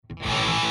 flak’n’roll-stiili